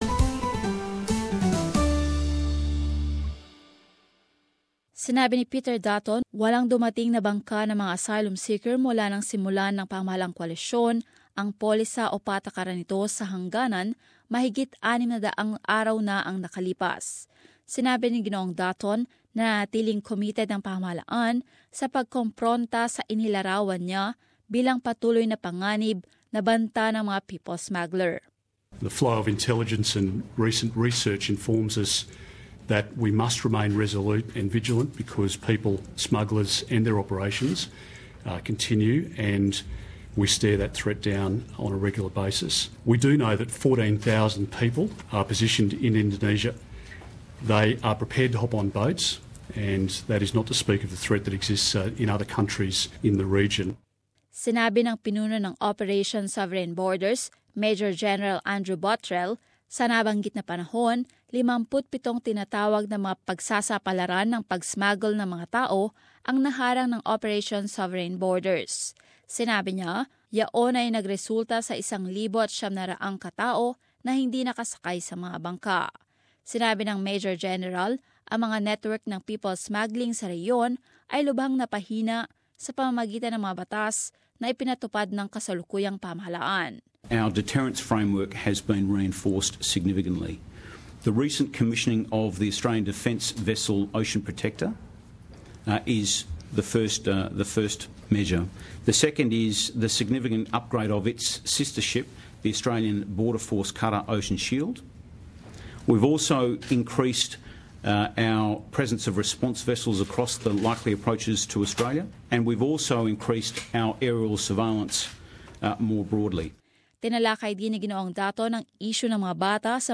In this report, Mr Dutton made the comments in an update on asylum seeker and refugee issues.